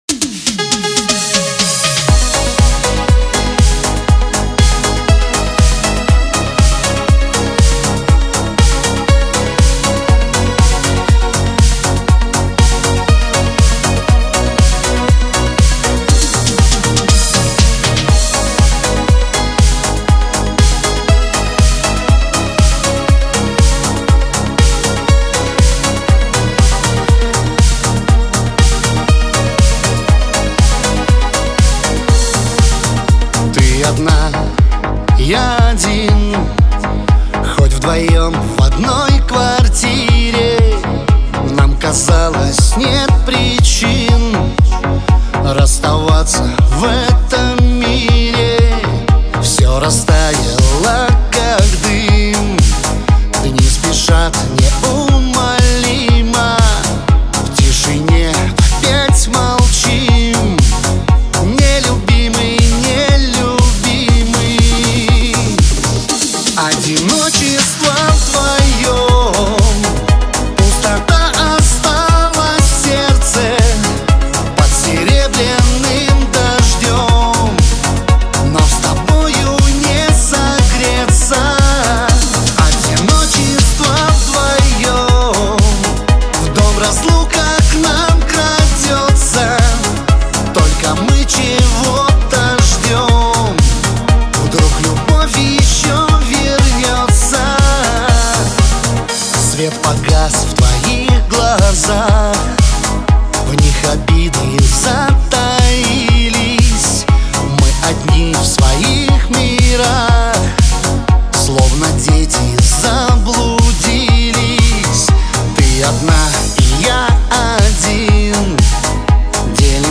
выполненная в жанре поп-рок.